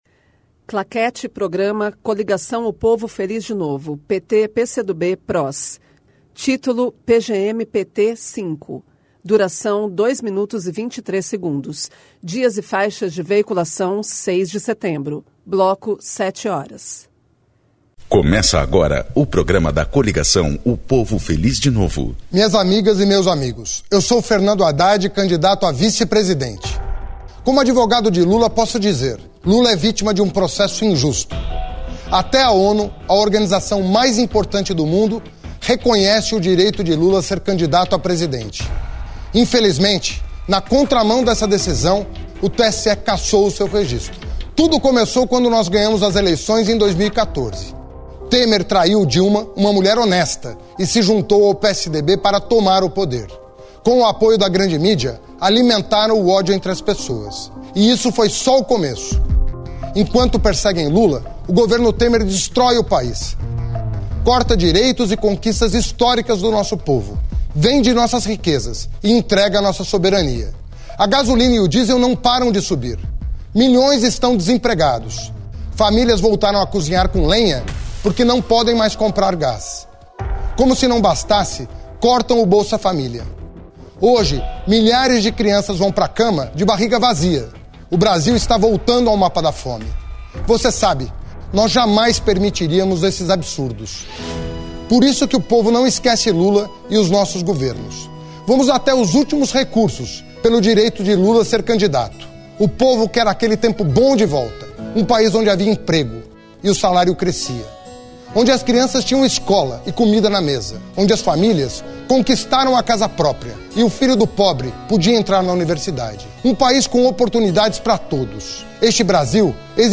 Descrição Programa de rádio da campanha de 2018 (edição 05) - 1° turno